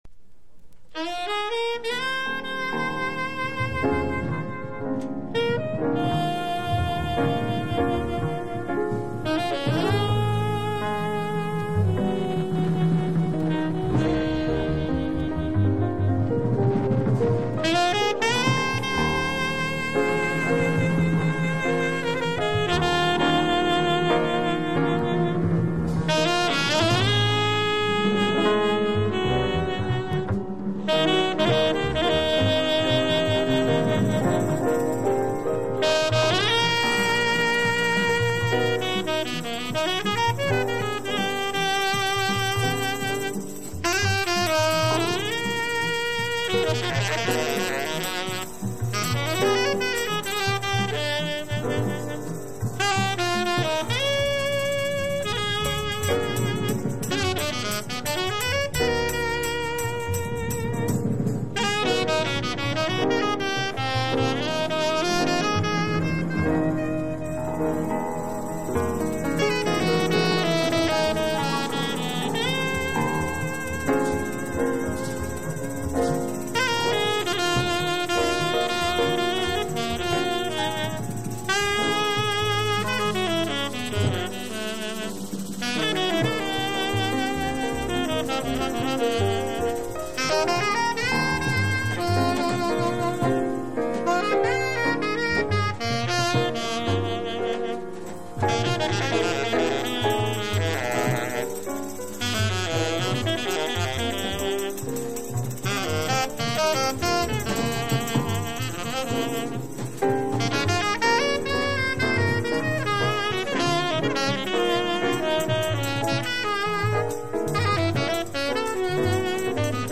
（プレスによりチリ、プチ音ある曲あり）
Genre SPIRITUAL JAZZ